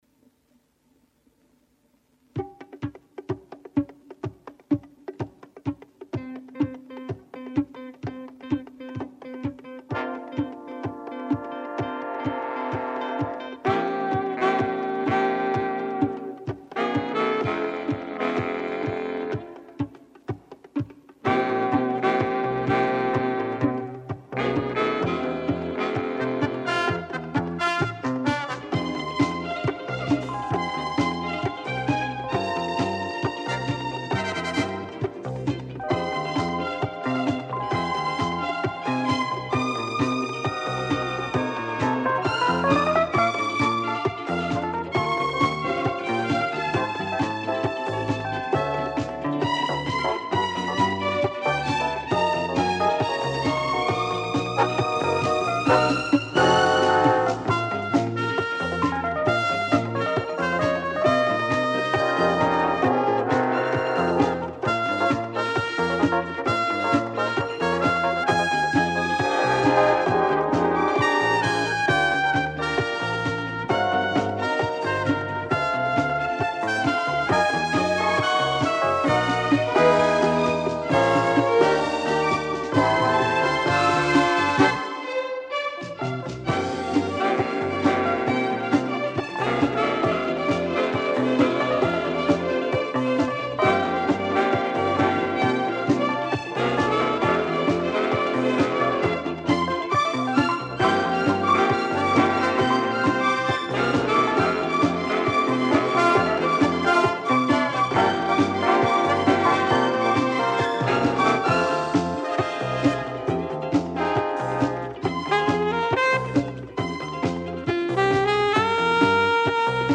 Вот запись с моей пластинки.